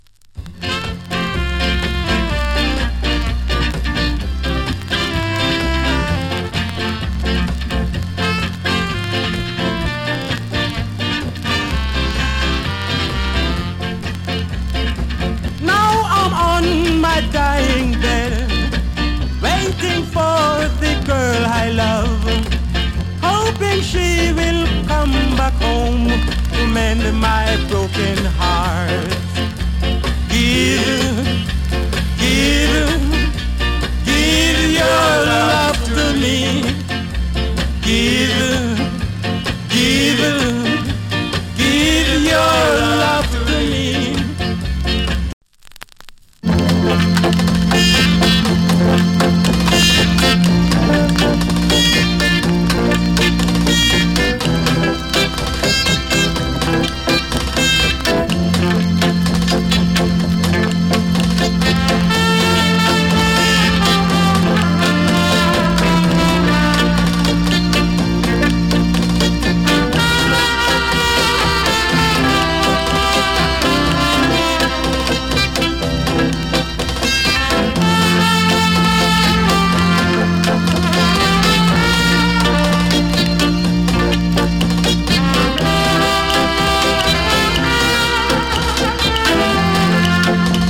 チリ、パチノイズ有り。
NICE VOCAL SKA !!
UK物 SKINHEAD REGGAE ファン要チェック !